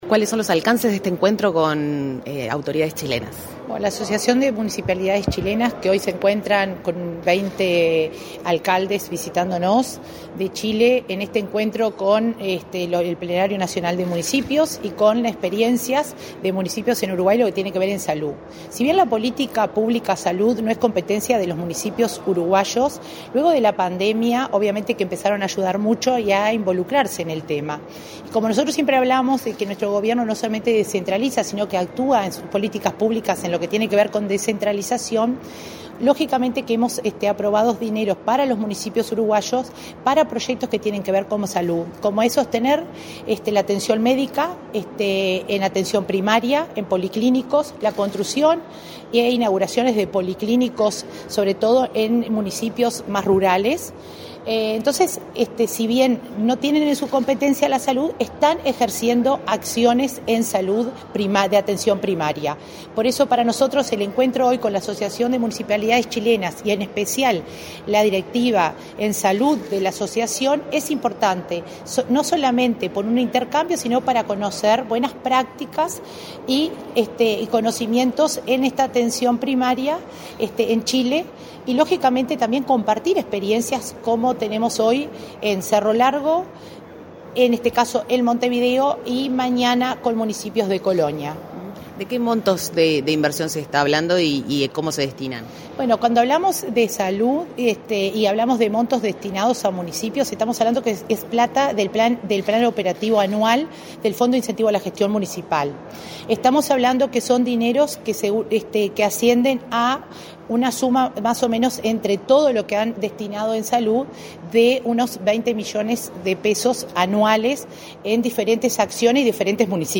Entrevista a la coordinadora de la OPP, María de Lima
La Oficina de Planeamiento y Presupuesto (OPP) recibió a autoridades de la Asociación de Municipalidades de Chile para compartir con el Plenario de Municipios de Uruguay las experiencias de actividades enfocadas a la salud en los gobiernos locales. La coordinadora general del Área de Descentralización y Cohesión de la OPP, María de Lima, explicó, a Comunicación Presidencial, el alcanse de la reunión.